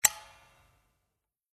"Schott" hair dryer
on-and-off-button.mp3